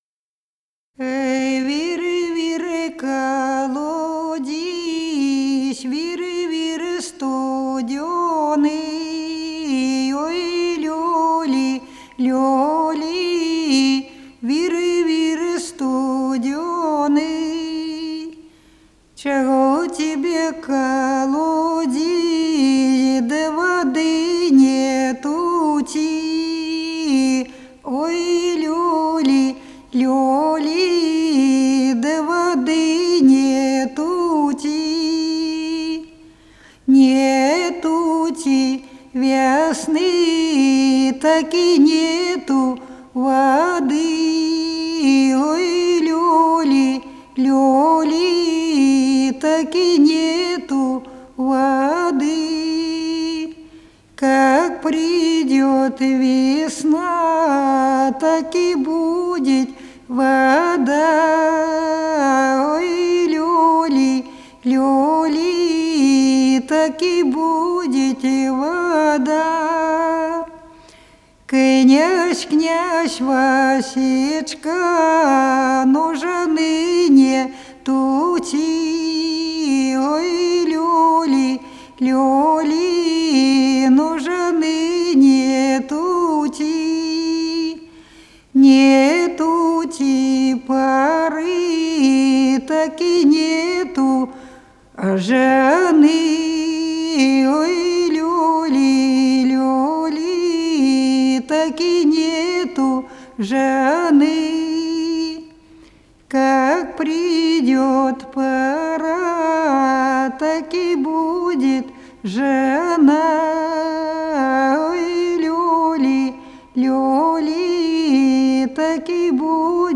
Народные песни Смоленской области